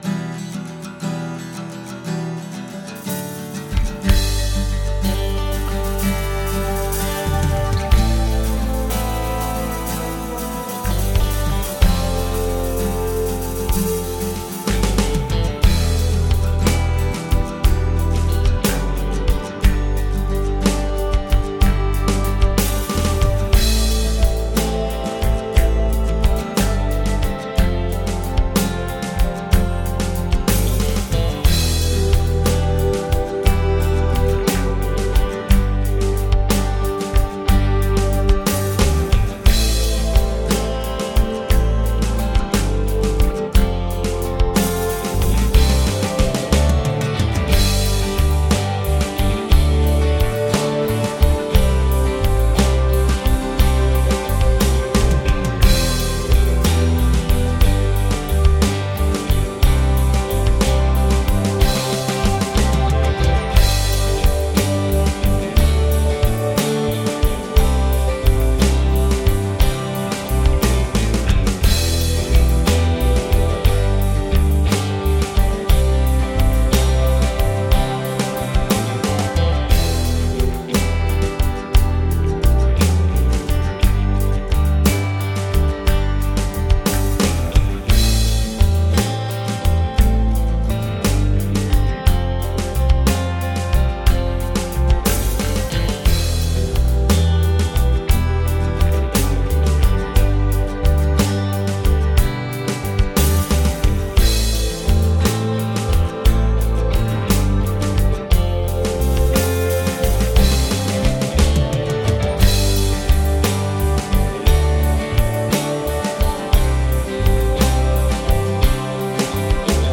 Fender '59 Bassman LTD